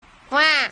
PLAY Pokemon Psyduck Cry
Play, download and share Psyduck original sound button!!!!